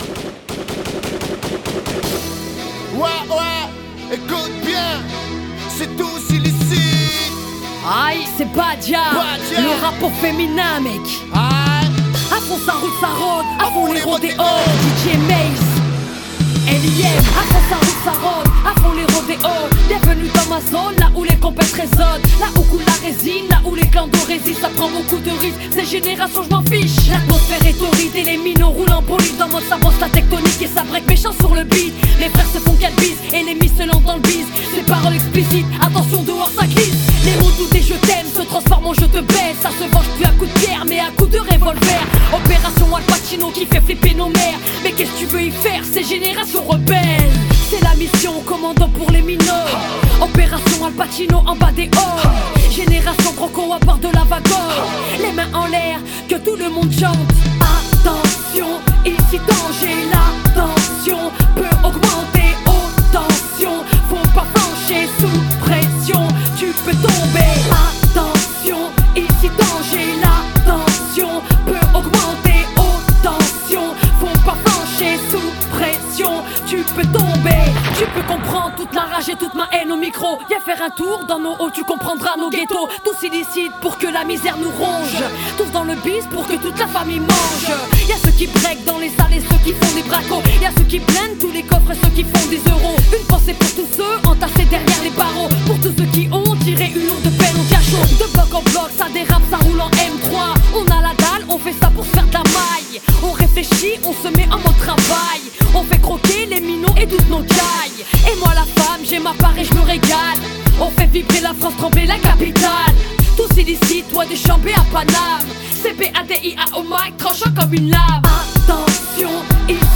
le rap au féminin